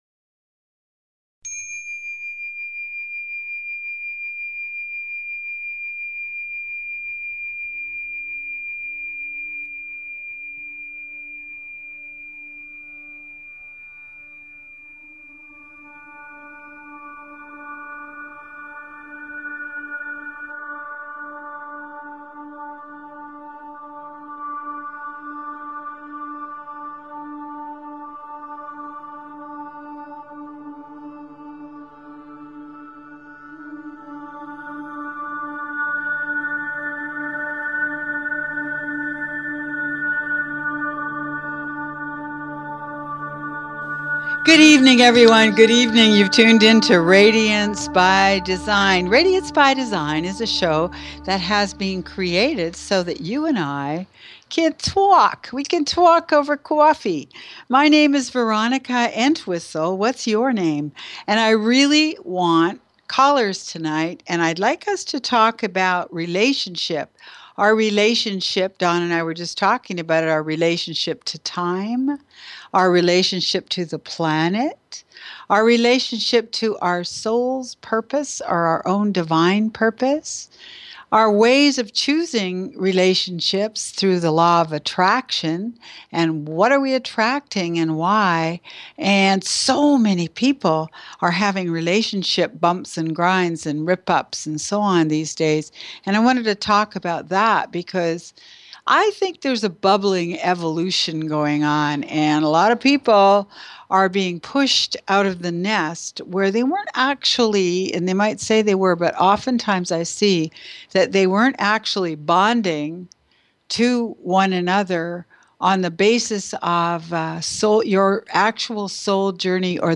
Talk Show Episode
Taking Questions from callers!